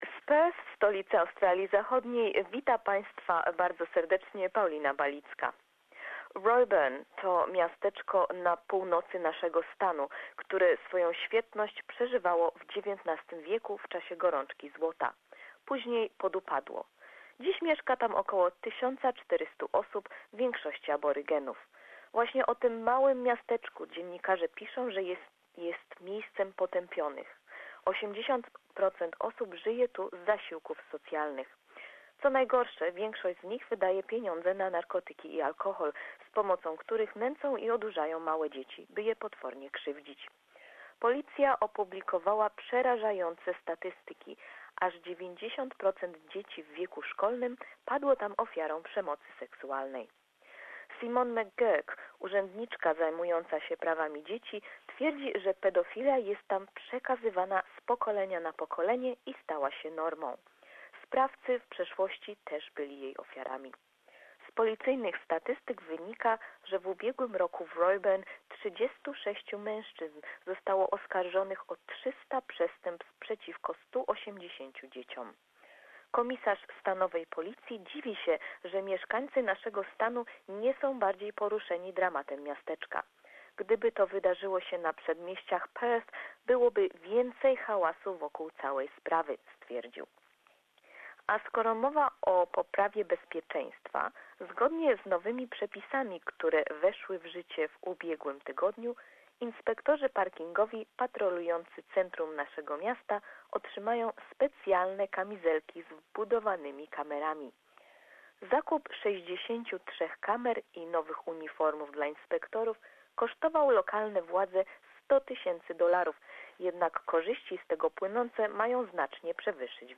Report from Western Australia